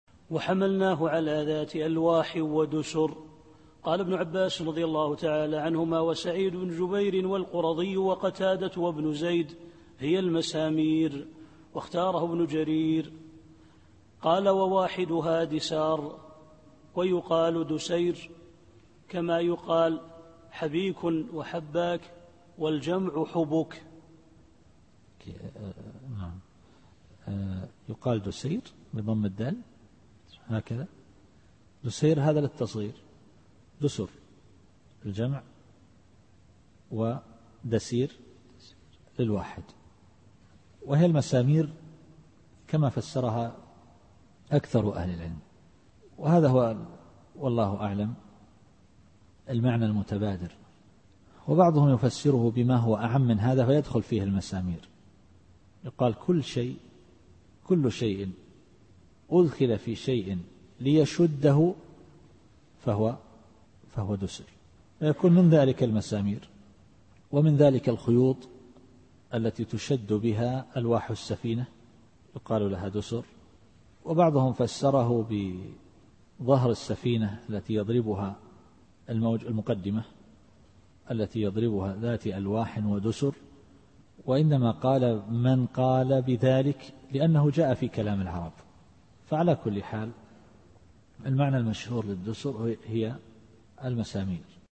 التفسير الصوتي [القمر / 13]